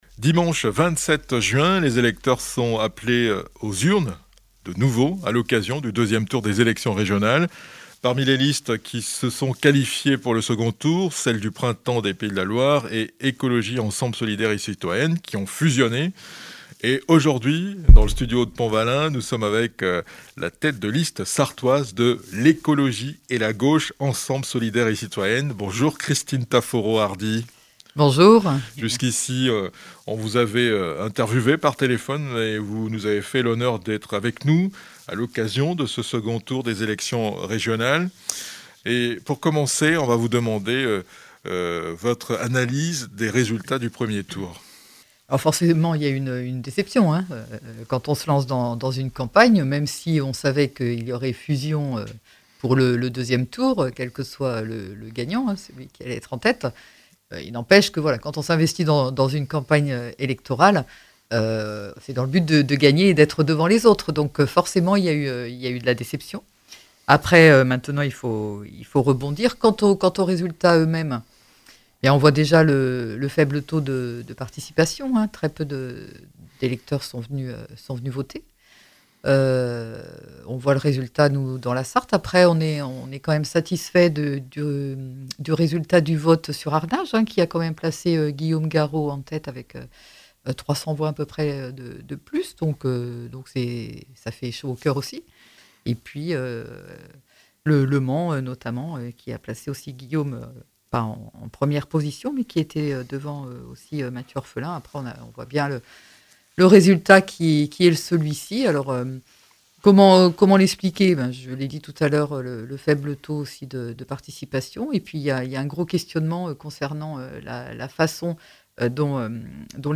Elections régionales : entretien